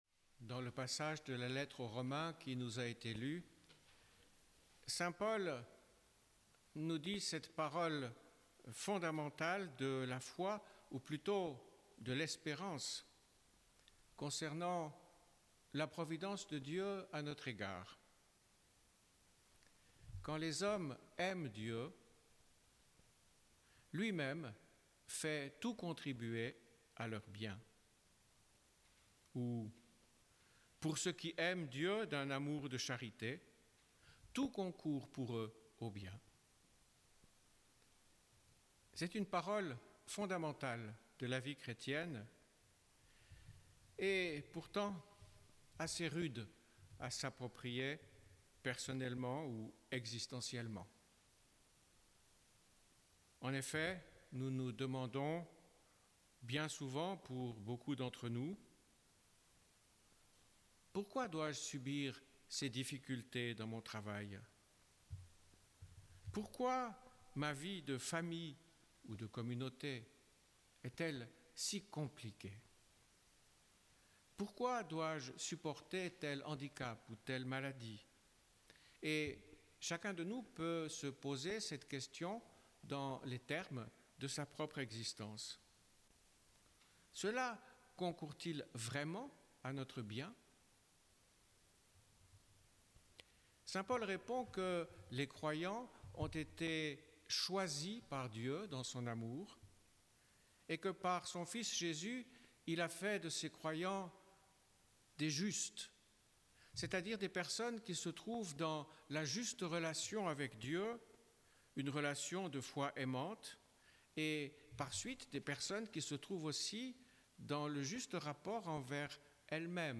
Enregistrement en direct